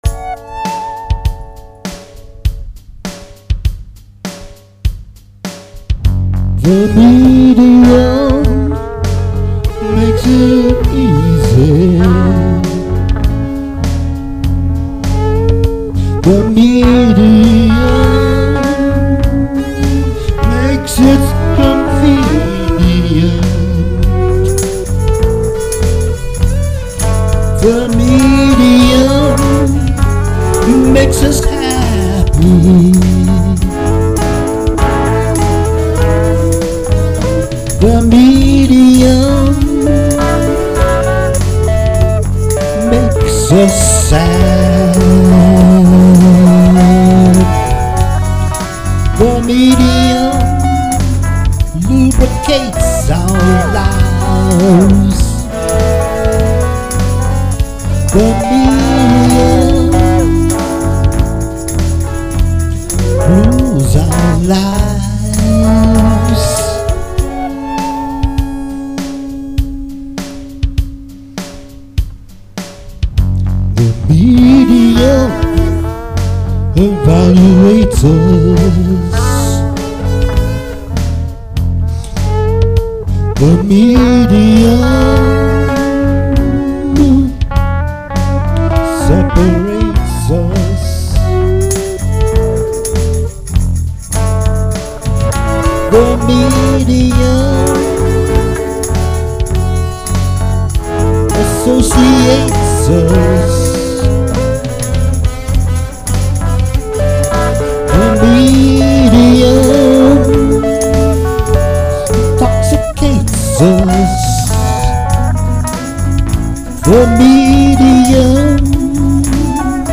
Chet Atkins Gretsch with Vox drone1
Meinl shakers, LP shaker, Nux looper+drums.